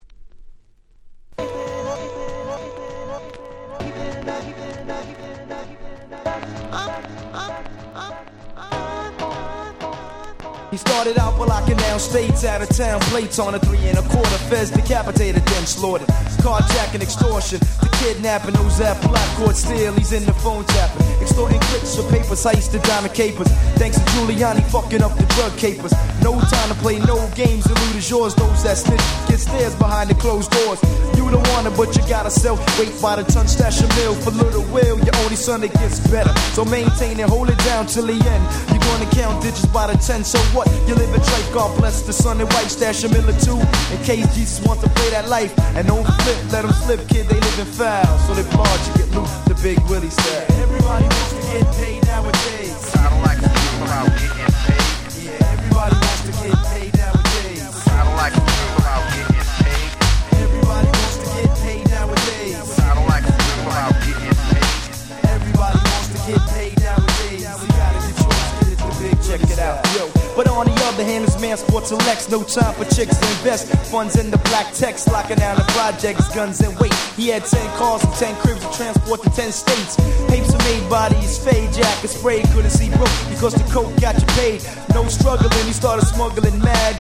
90's アンダーグラウンド アングラ　Boom Bap ブーンバップ